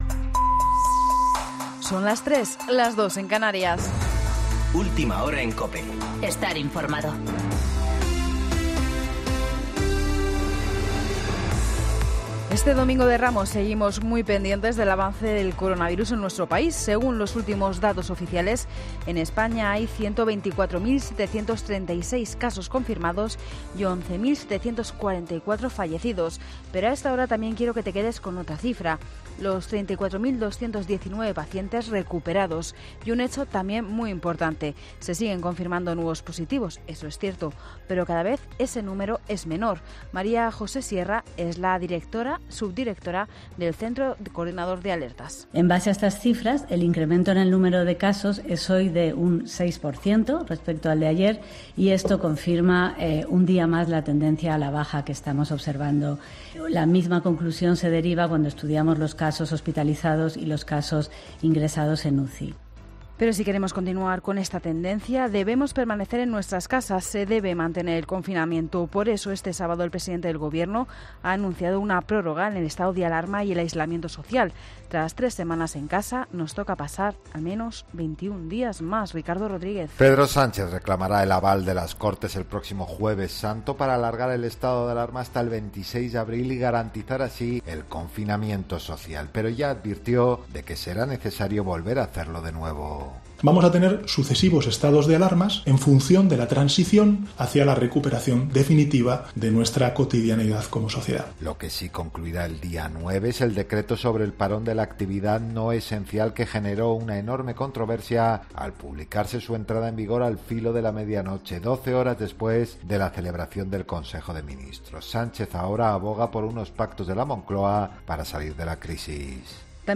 Boletín de noticias COPE del 5 de abril de 2020 a las 03.00 horas